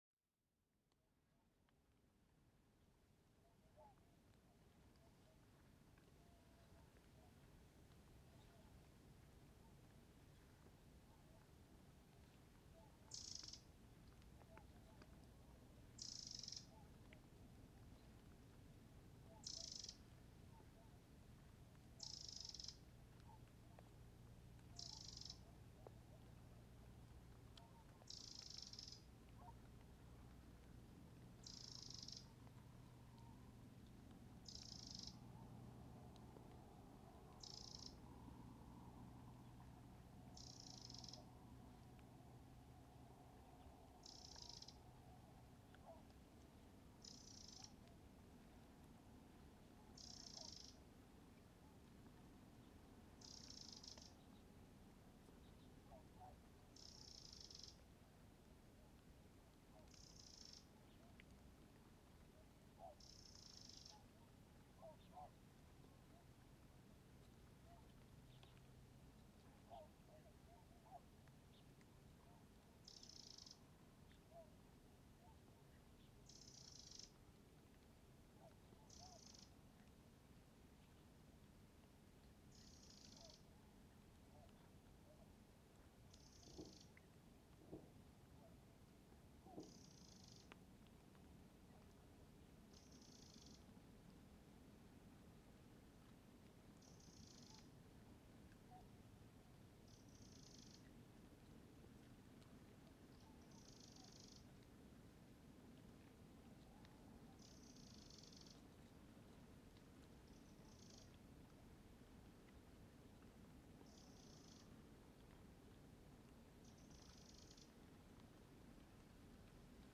In October 2013 I was recording over night in Stafholtsunga, west Iceland. I located the microphones in a ditch to avoid traffic noise as much as possible. But traffic noise all over the county and air conditioner at nearby farm infected this recording most of the time. The recording starts between 6 and 7am.
You will hear in several bird species like raven, starling and swans and birds activity near the microphones. Also barking dog, sheep and horses in distance. You will hear some dripping sound from the groundwater in the ditch. Gust will also gently weep grass and nearby bush.